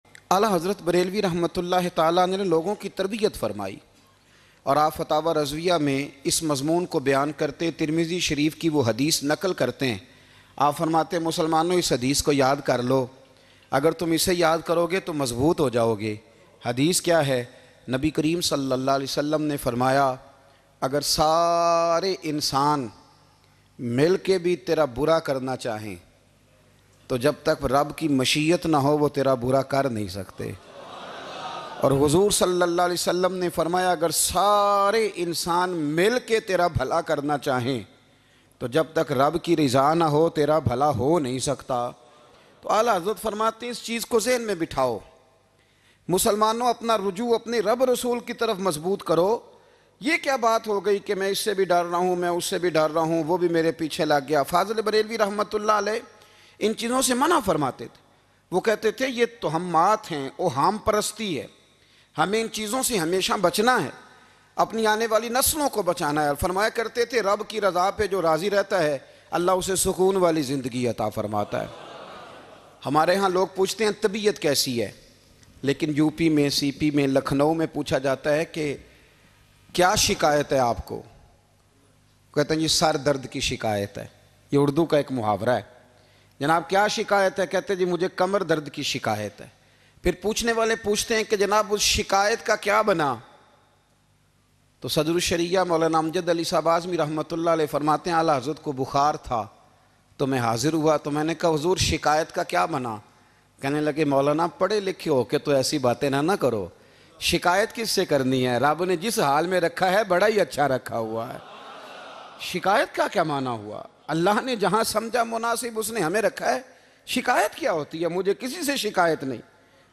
Aala Hazrat Sy Kisi Ny Poocha Aap Kis Tarha Ky Momin Hein Bayan MP3